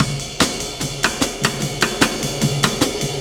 Jazz Loop 149.5.wav